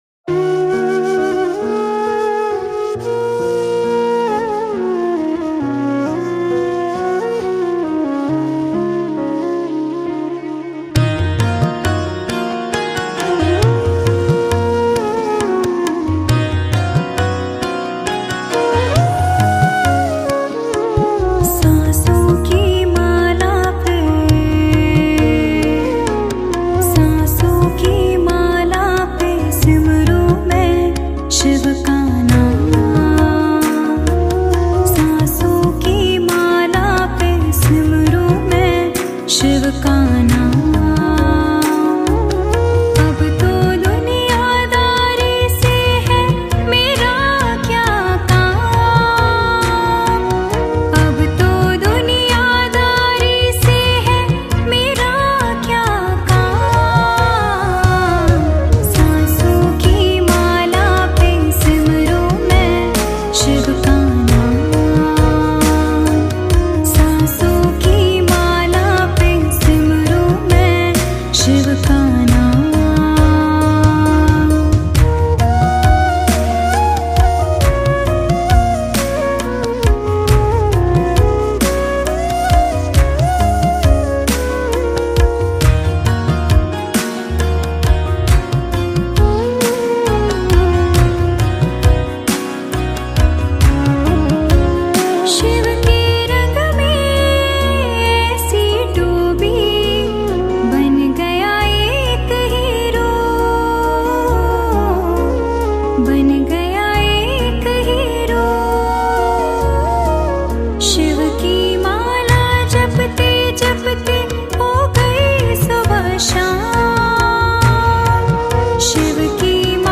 Devotional Songs